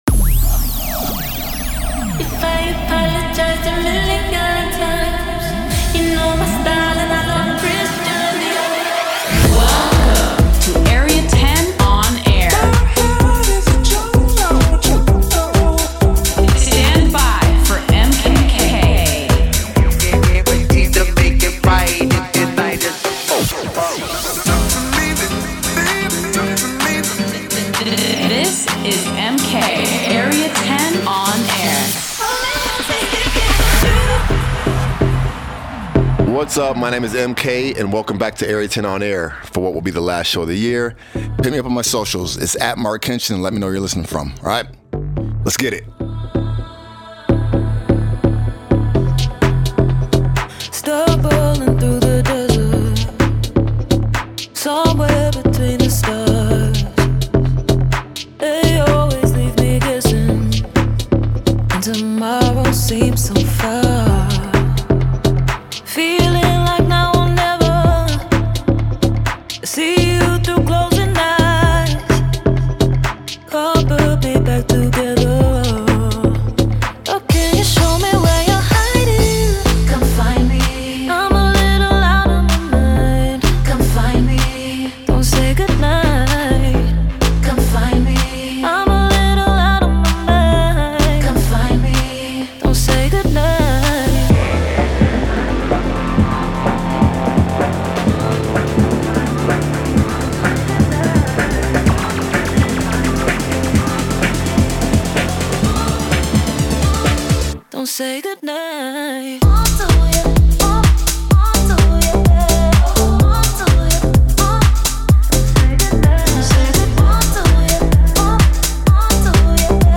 Delving in to house and techno